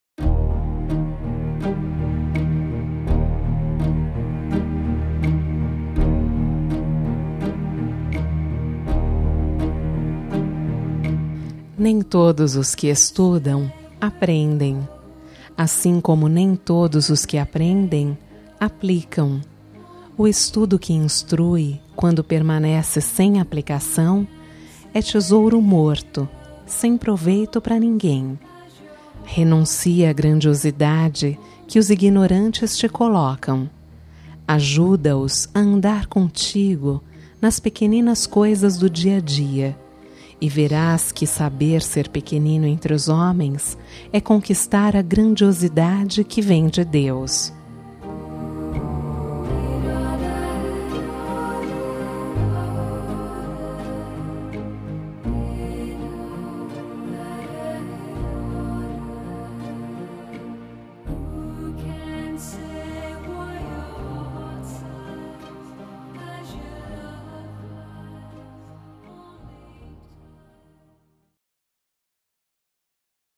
Telemensagem de Otimismo – Voz Feminina – Cód: 100101